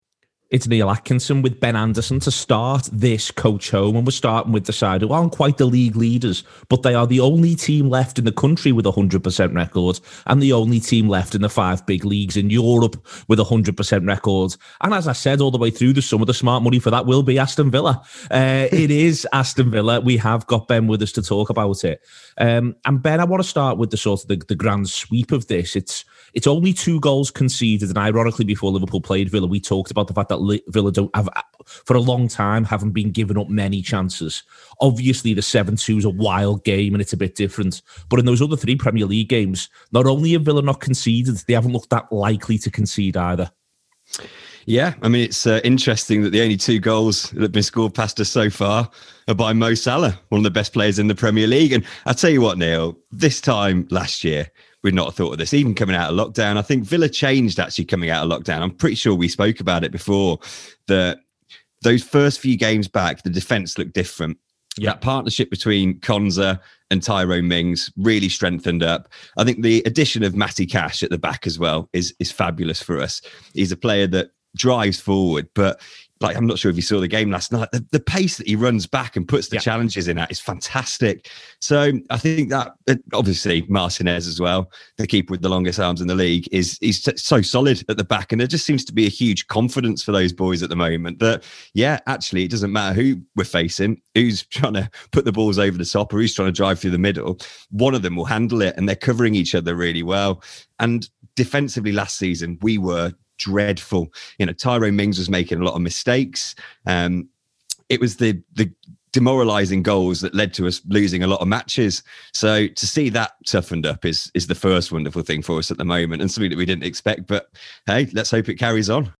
Southampton fan
Brighton fan